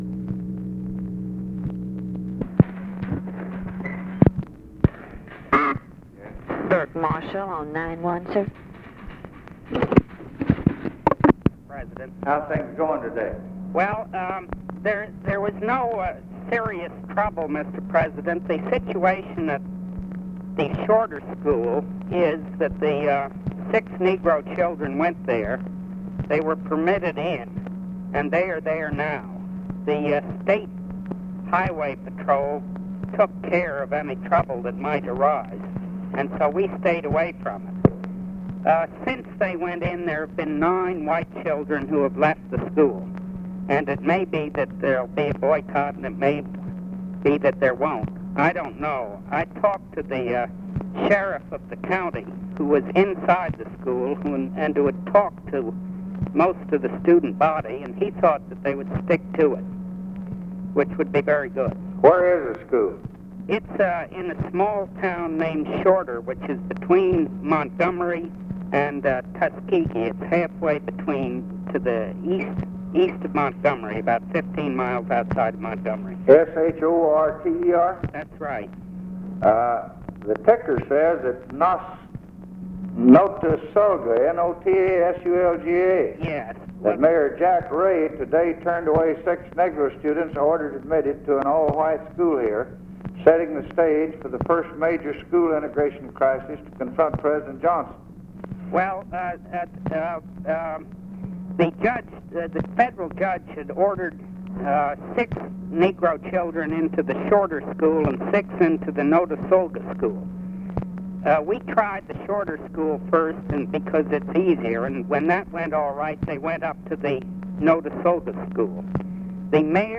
Conversation with BURKE MARSHALL, February 5, 1964
Secret White House Tapes